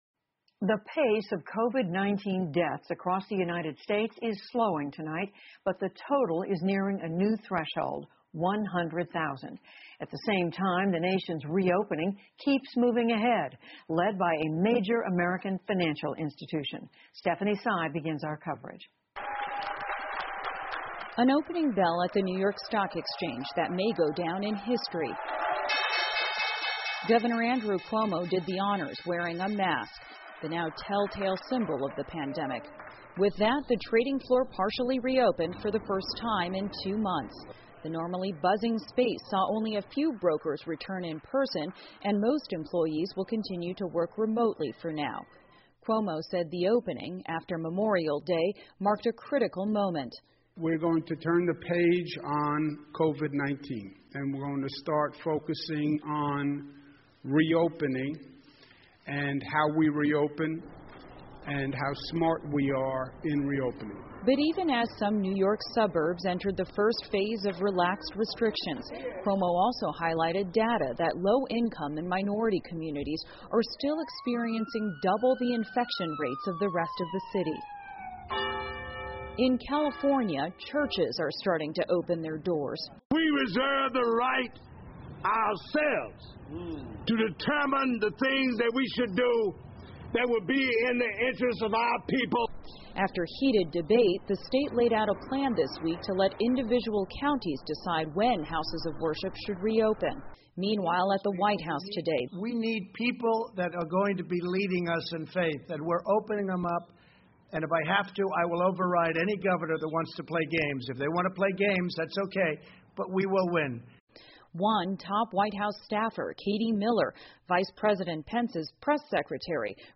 PBS高端访谈:美国新冠死亡人数开始下降 听力文件下载—在线英语听力室